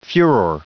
Prononciation du mot furor en anglais (fichier audio)
Prononciation du mot : furor